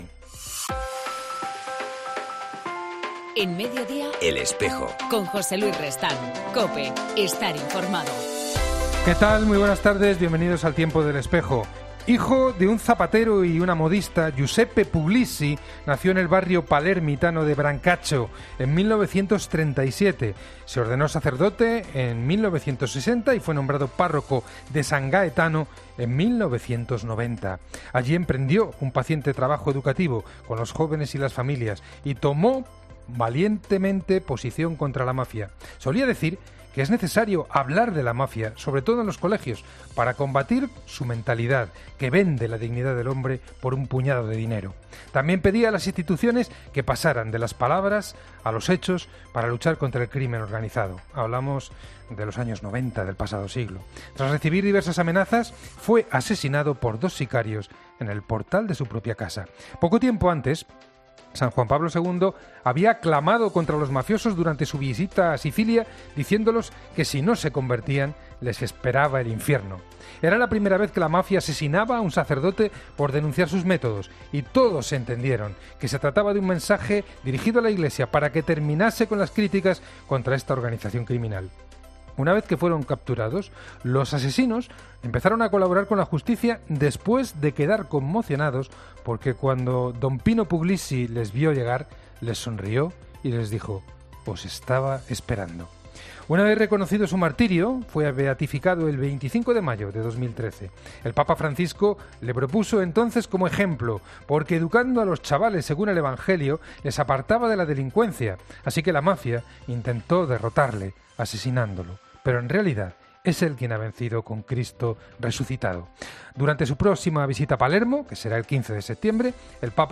hablamos con el religioso mercedario